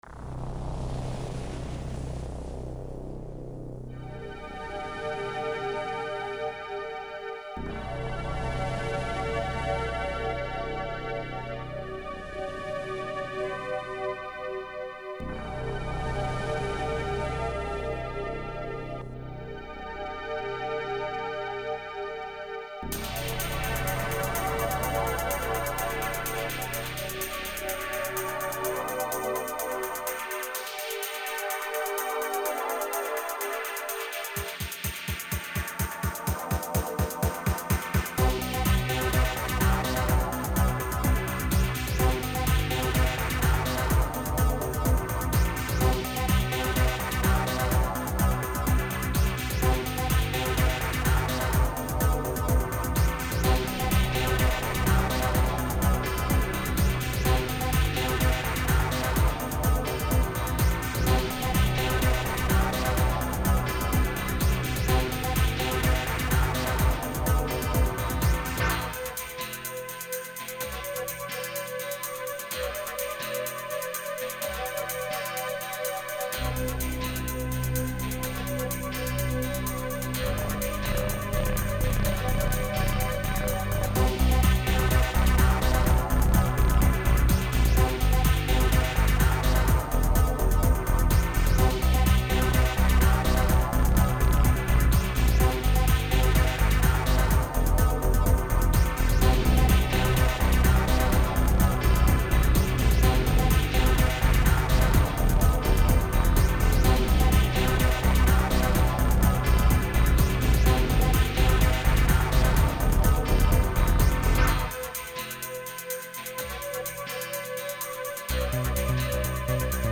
Based on the excellent original edited speech